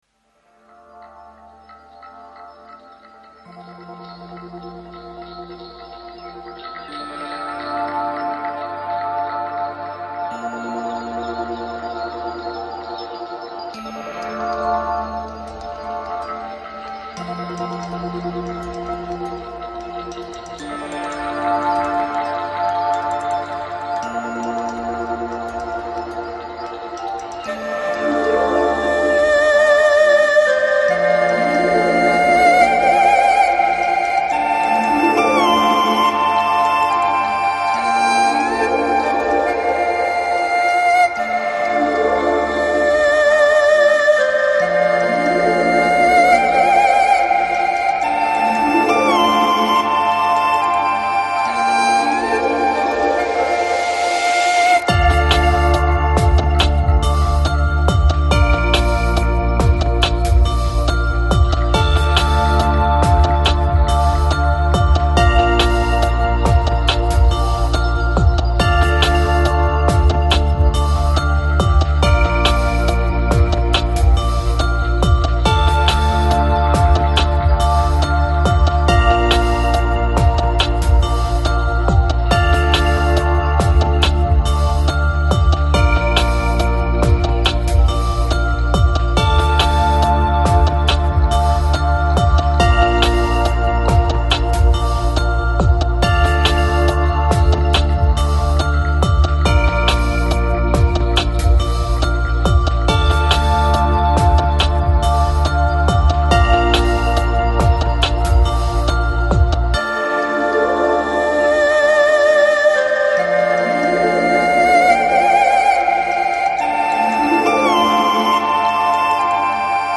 Жанр: Downtempo, Lounge, Chillout